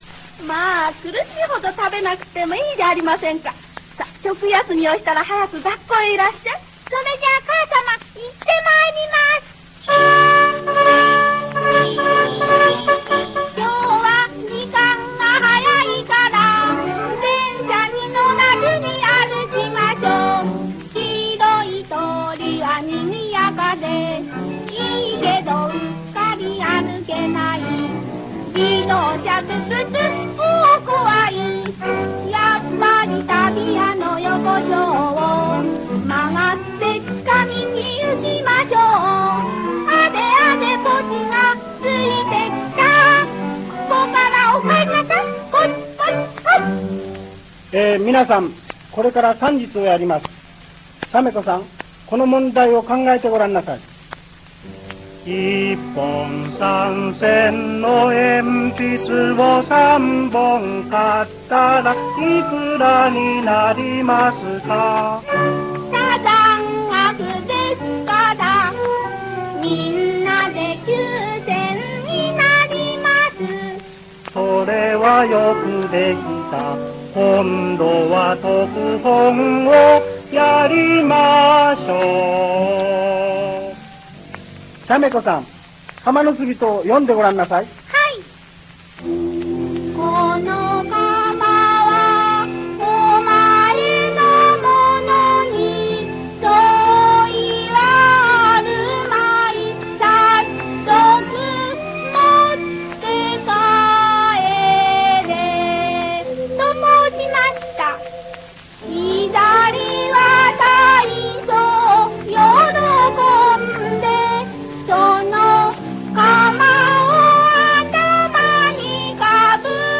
童謡唱歌
独唱